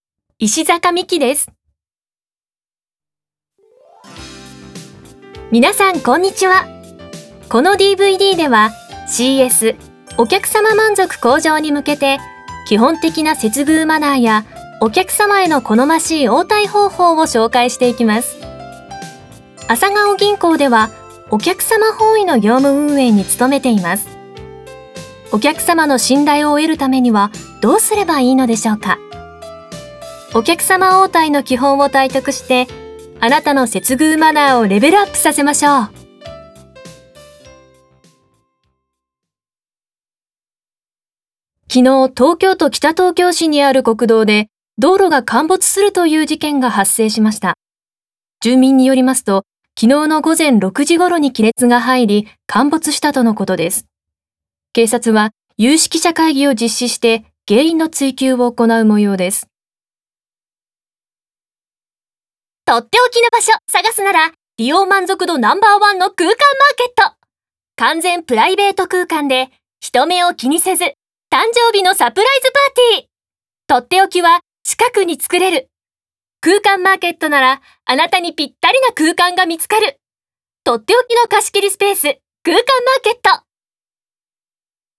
• ナレーター
VOICE SAMPLE